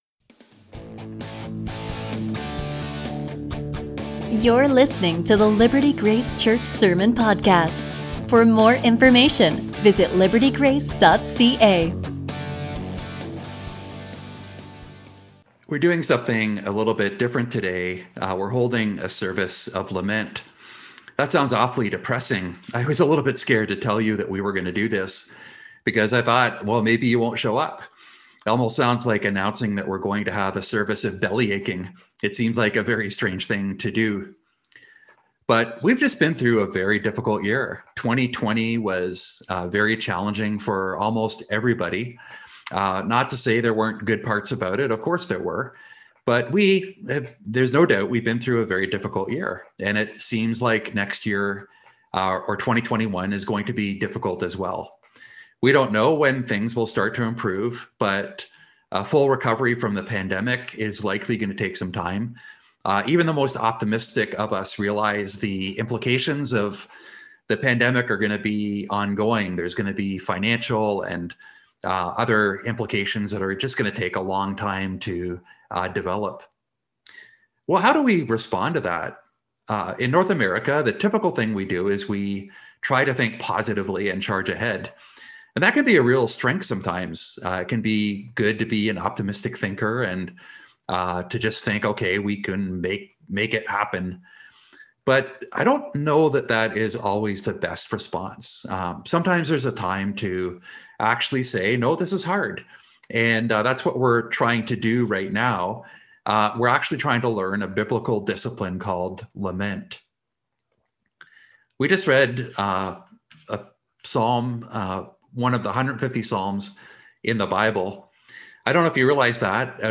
A sermon from Psalm 13